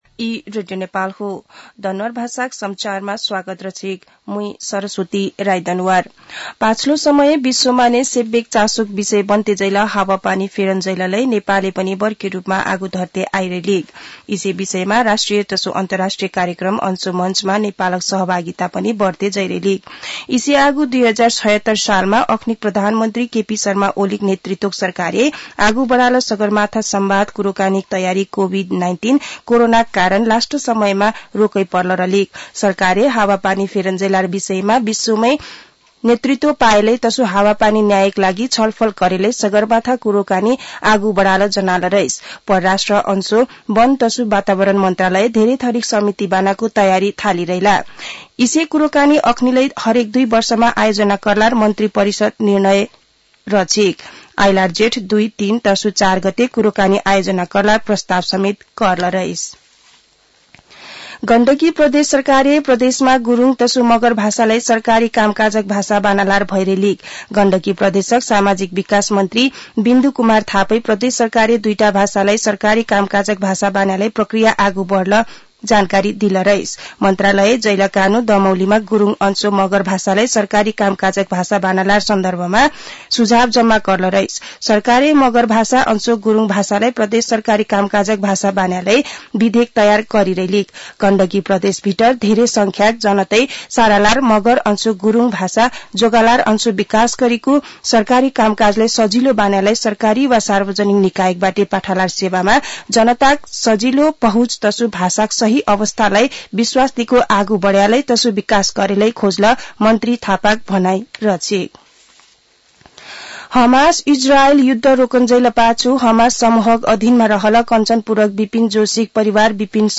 दनुवार भाषामा समाचार : ८ माघ , २०८१
Danuwar-news-1-3.mp3